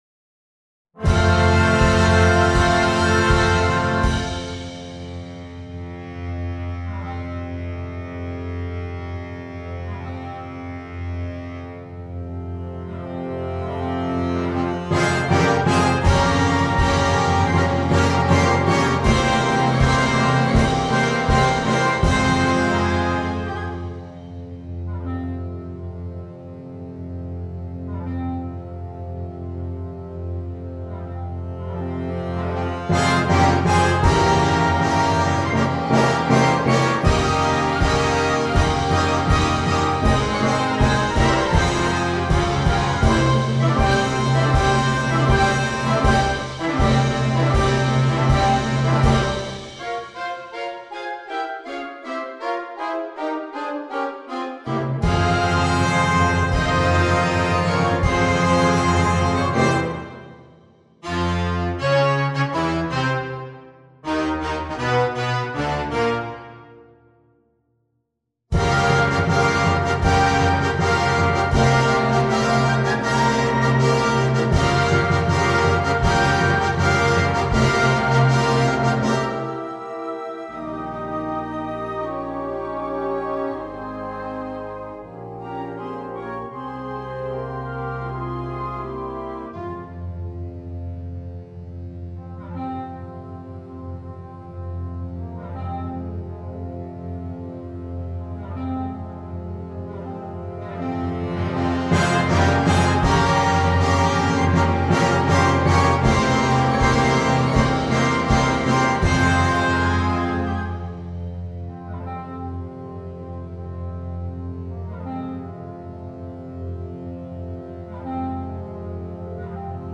per coro (ad. lib) e banda